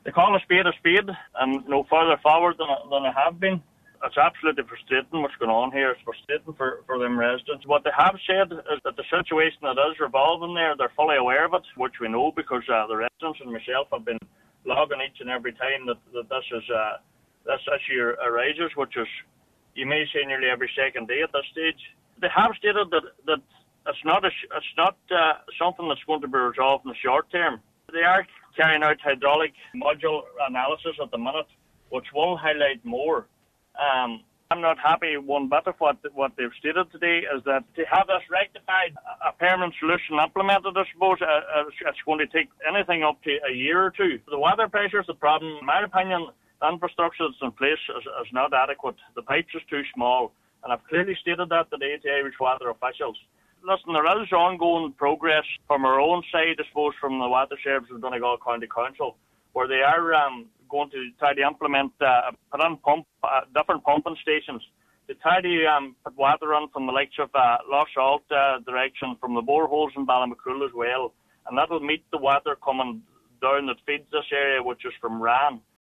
Cathaoirleach of the Letterkenny Milford MD Councillor Donal Mandy Kelly says some interim works will be carried out: